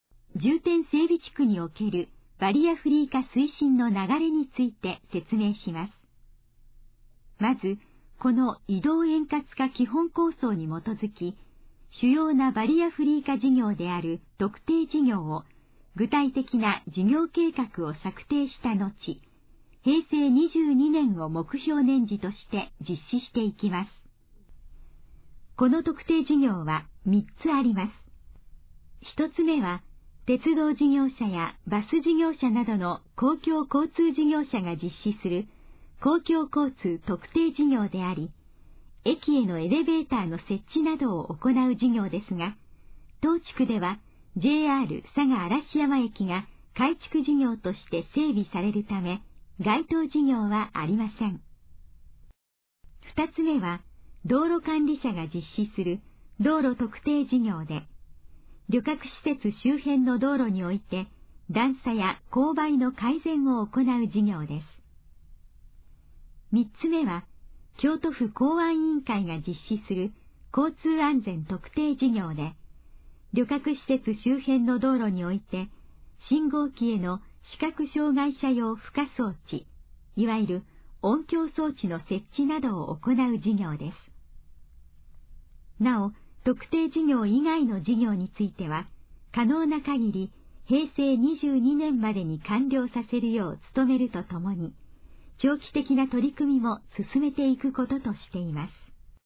このページの要約を音声で読み上げます。
ナレーション再生 約194KB